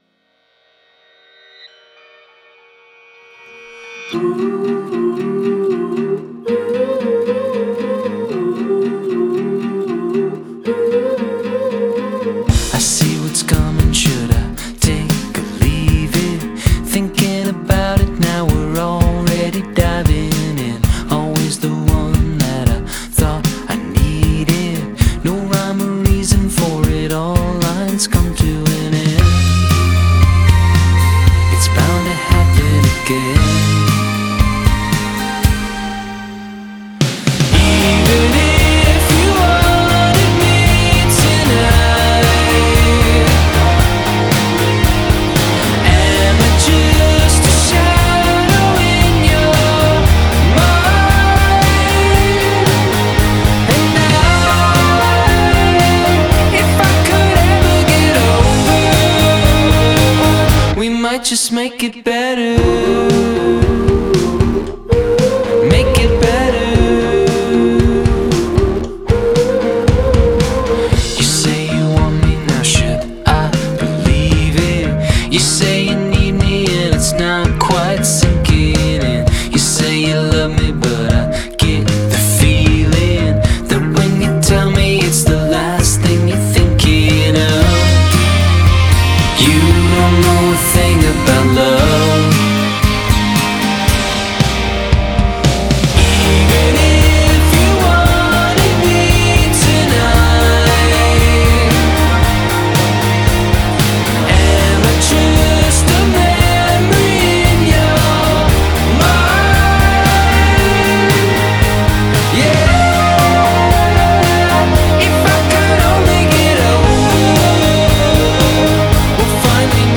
sweet vocal harmonies and melody-driven tune-age
spooky background vocals